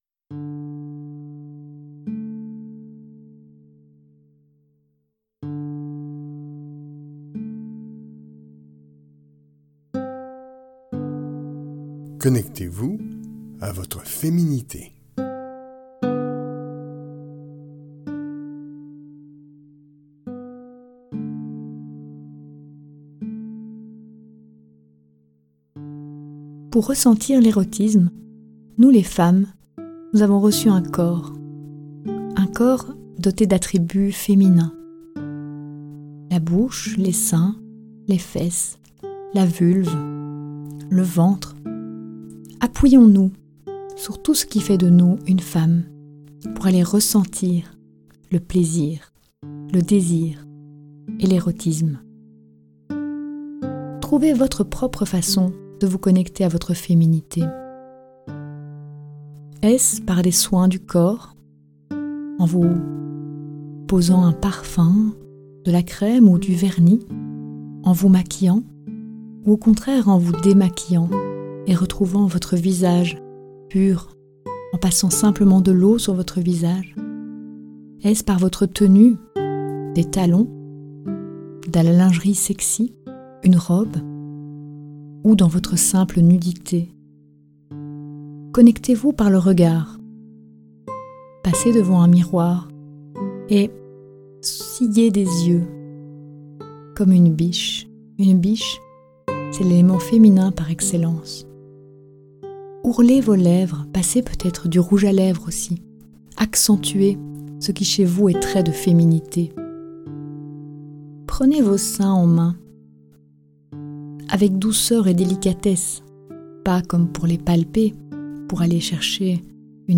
A travers de courtes méditations guidées, nous invitons les femmes à revenir en elles et à se connecter à leur désir et leur sensualité.